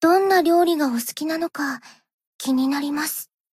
贡献 ） 分类:蔚蓝档案语音 协议:Copyright 您不可以覆盖此文件。
BA_V_Fuuka_Relationship_Up_3.ogg